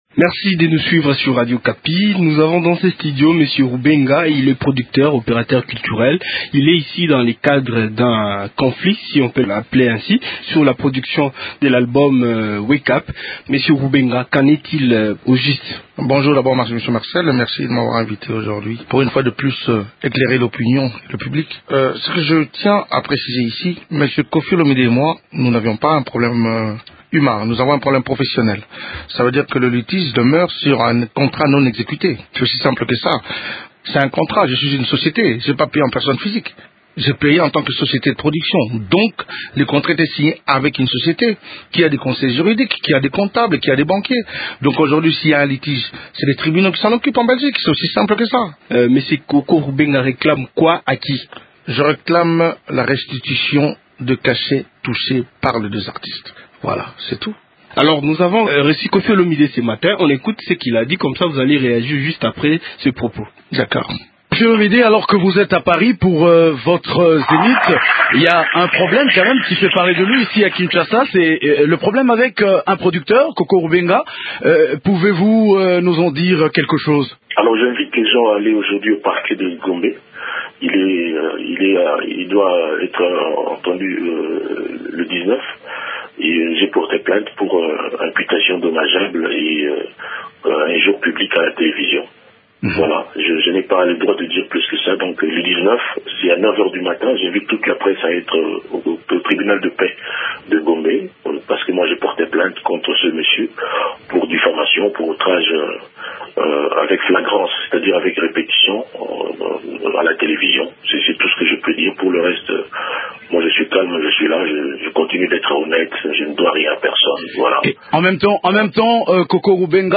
Leurs réactions, dans cette interview